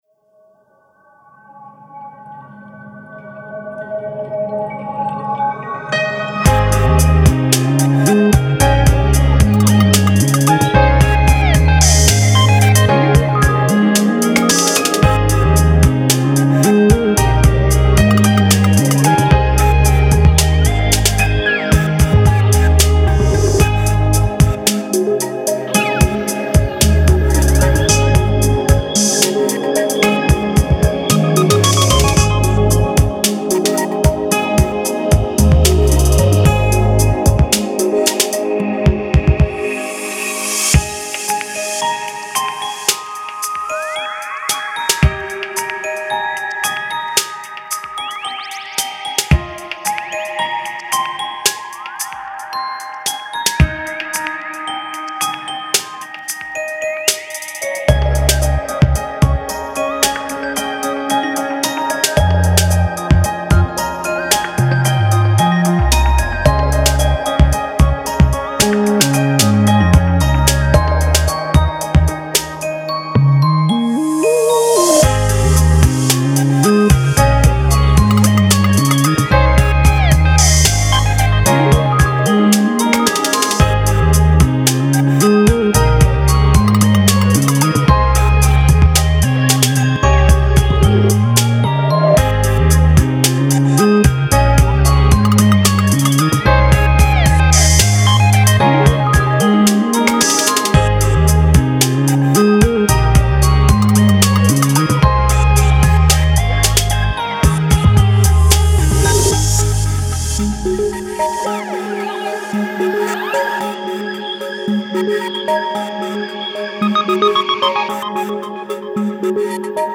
海中遊泳をイメージした癒し系＆幻想的な楽曲です。